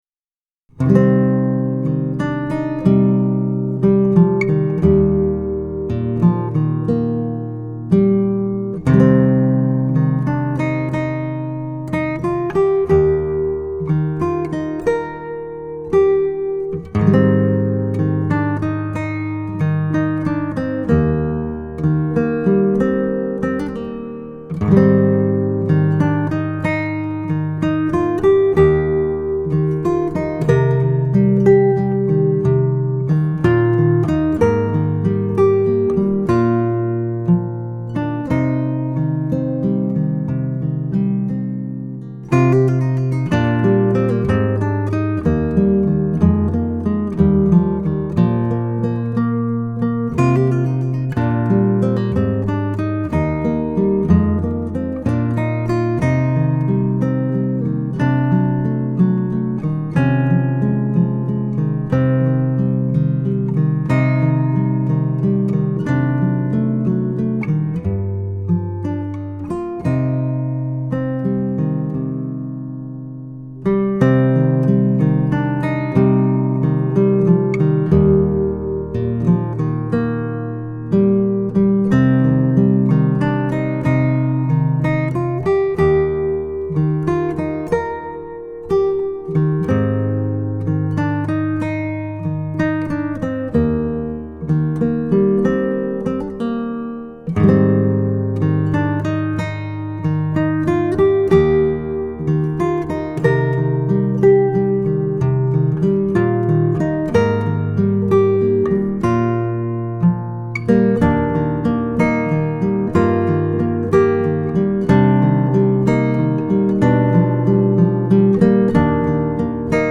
آرامش بخش
گیتار